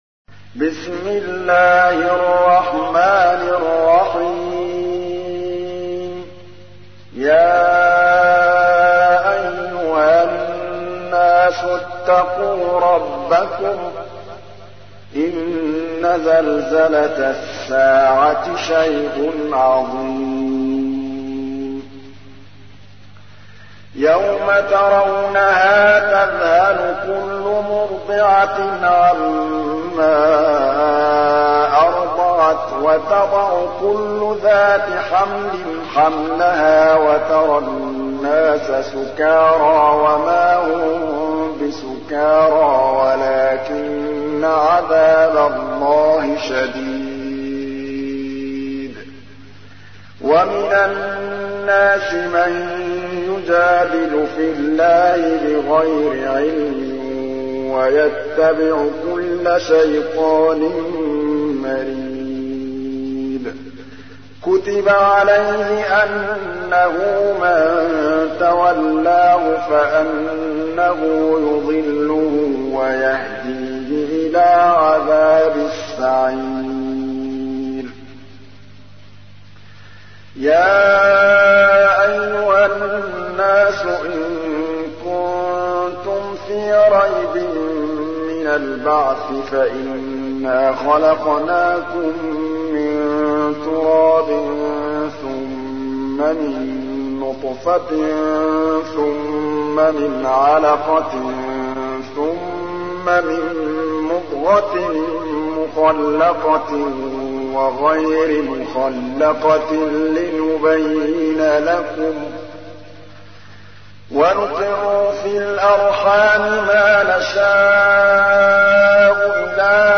تحميل : 22. سورة الحج / القارئ محمود الطبلاوي / القرآن الكريم / موقع يا حسين